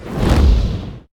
fire1.ogg